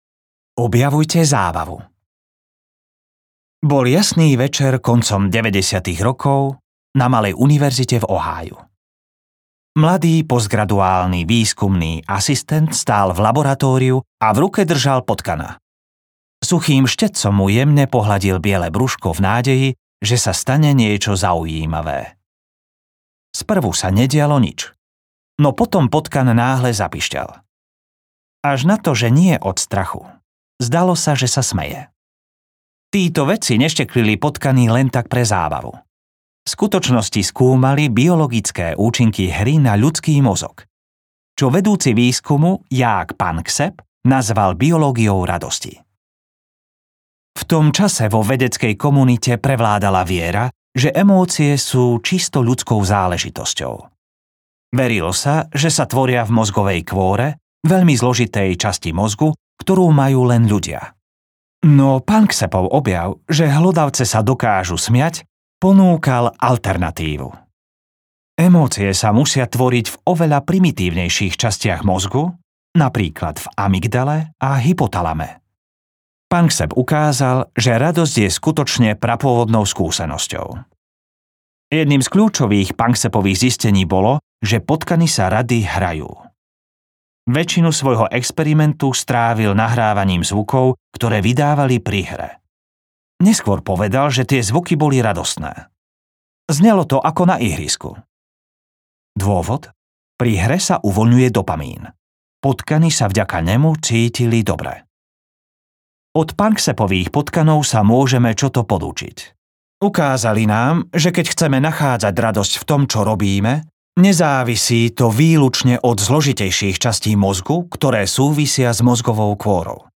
Produktivita bez starostí audiokniha
Ukázka z knihy